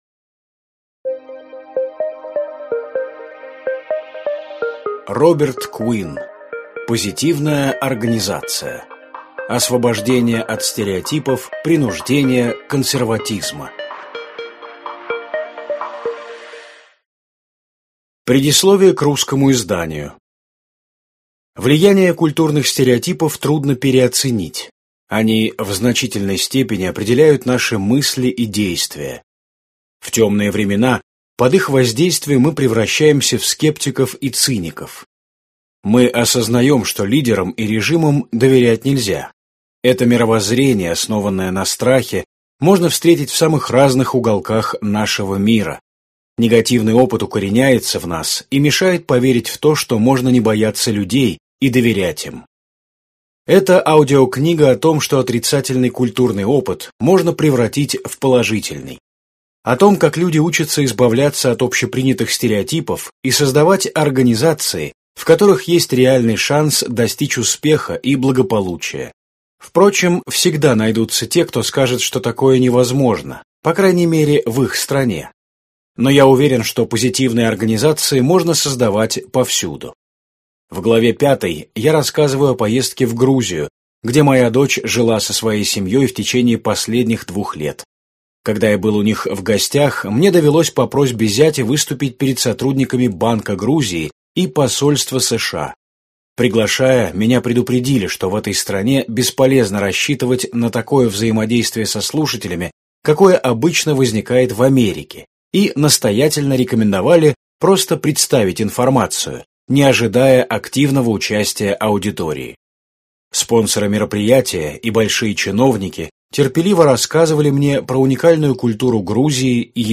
Аудиокнига Позитивная организация: Освобождение от стереотипов, принуждения, консерватизма | Библиотека аудиокниг